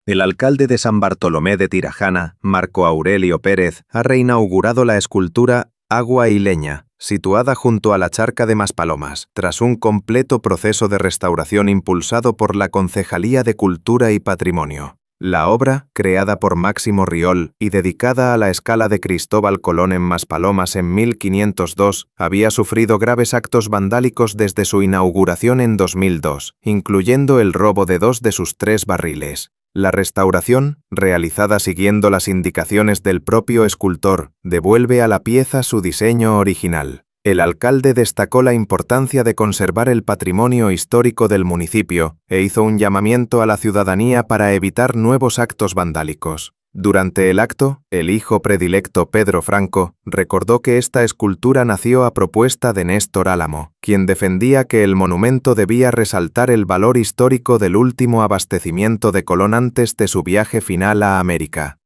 AUDIO-ACTO-REINAUGURACION-ESCULTURA-CRISTOBAL-COLON.mp3